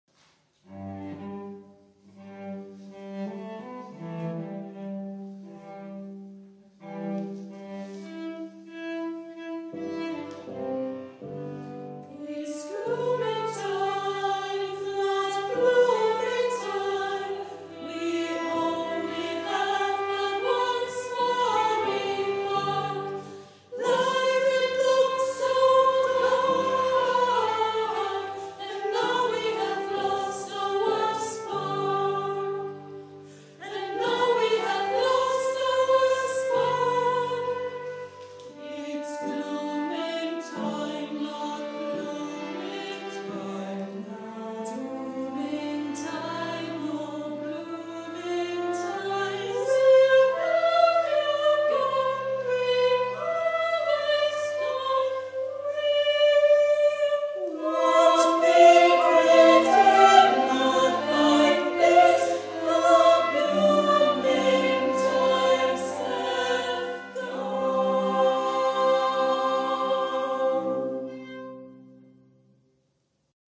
One Out of Ten (2007) – (10-Minute Musical for children)
It’s glooming time  (perfomed live)